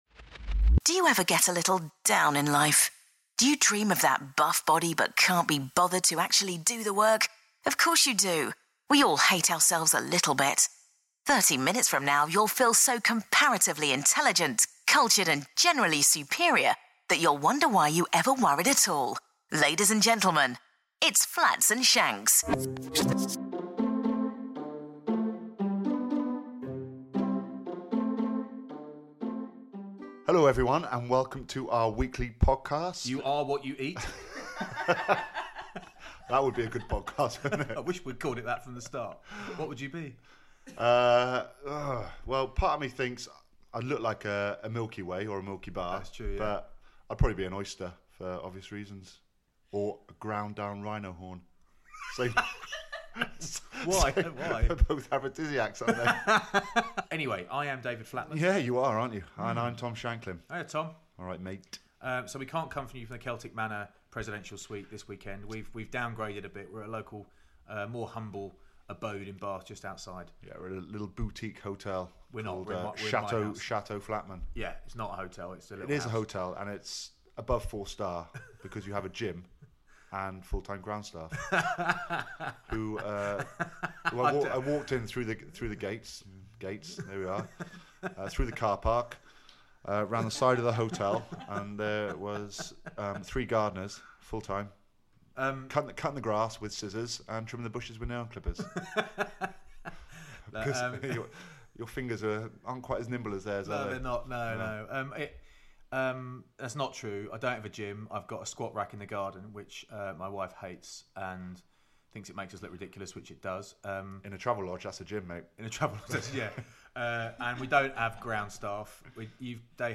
Two men incapable of performing a Welsh accent become omniscient for a while. They also talk to Jamie Roberts, who’s undeniably lovely.